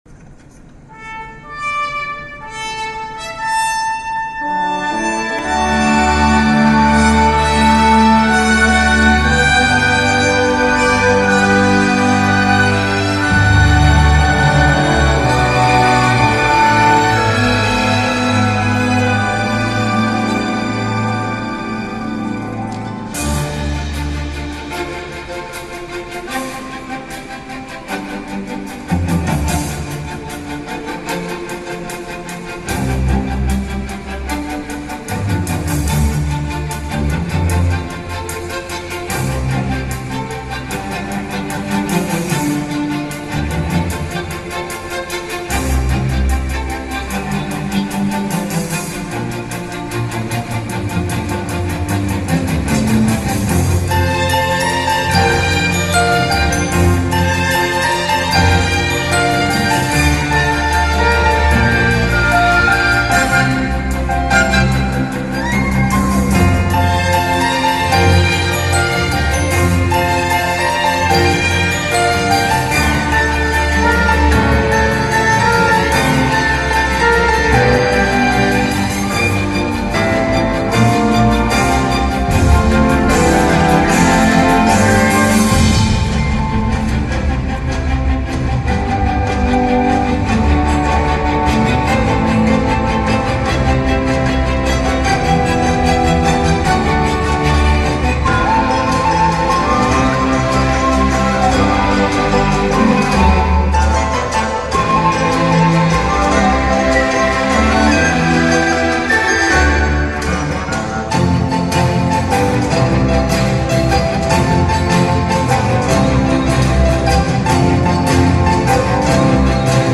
Live-Performance